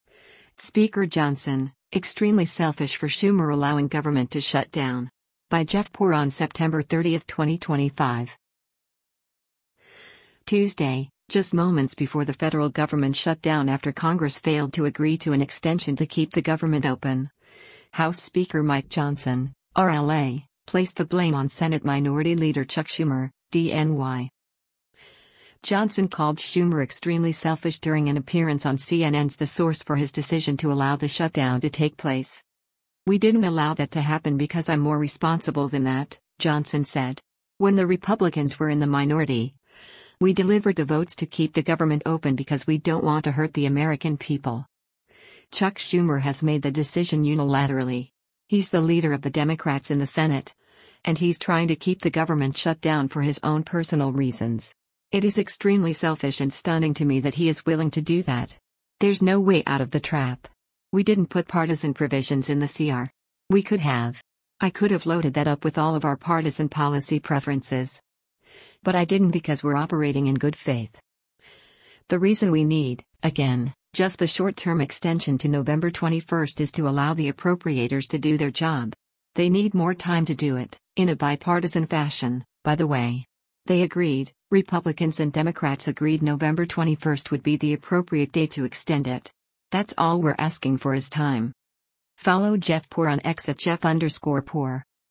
Johnson called Schumer “extremely selfish” during an appearance on CNN’s “The Source” for his decision to allow the shutdown to take place.